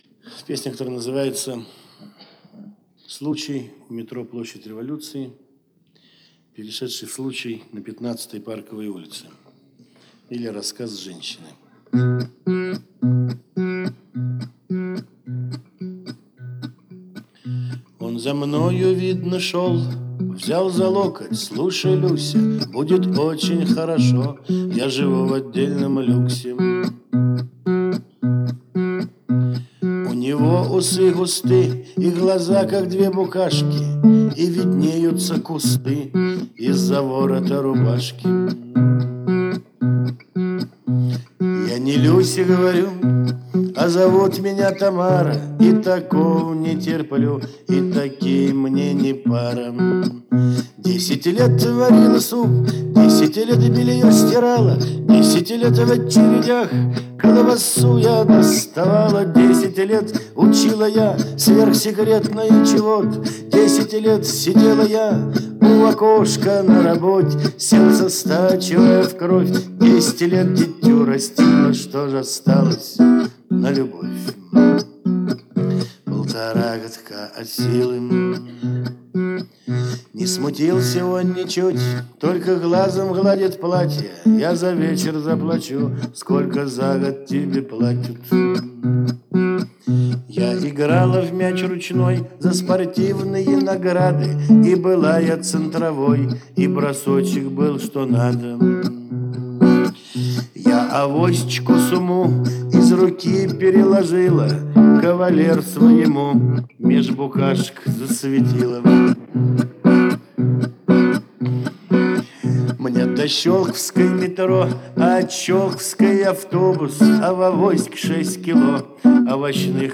С концерта